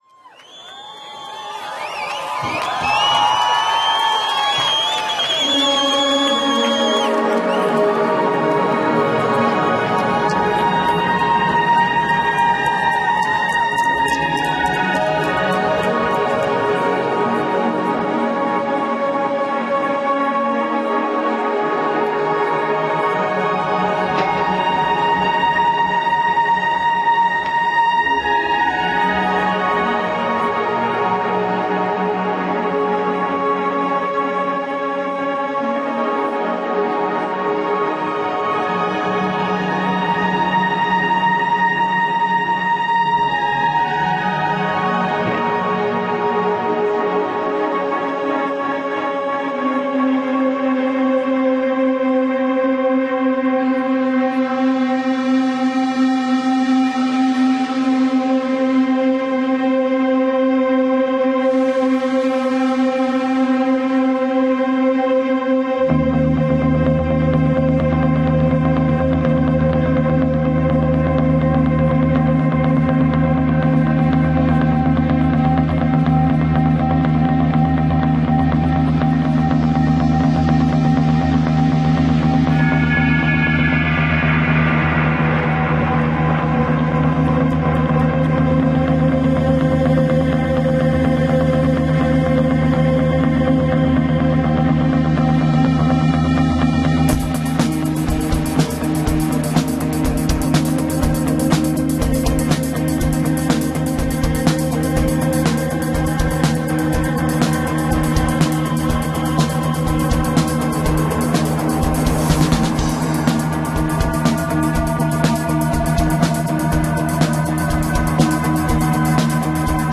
recorded in Paris on December 10, 2013.
the harder edge of Psychedelia.